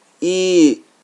File File history File usage Trây_i_long.ogg (file size: 18 KB, MIME type: application/ogg ) Trây vowel /ii/ File history Click on a date/time to view the file as it appeared at that time.
Trây_i_long.ogg